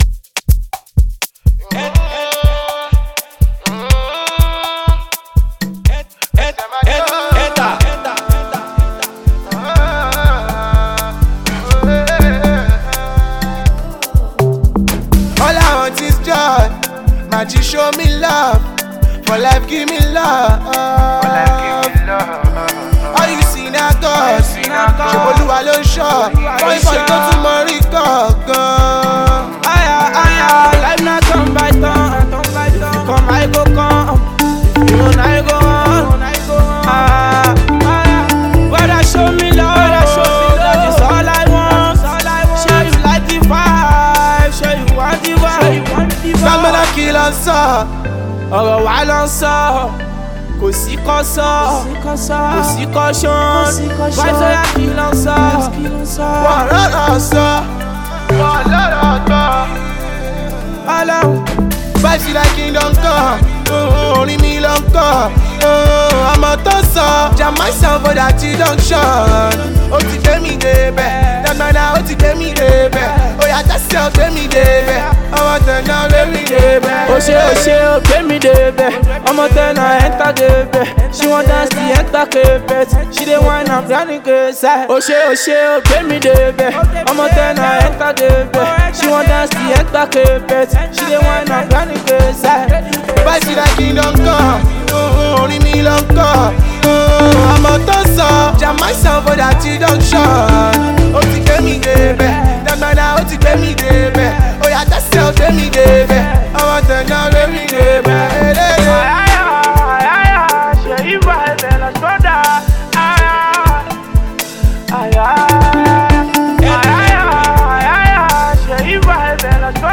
a prefect blend of Afropop, Street pop and Amapiano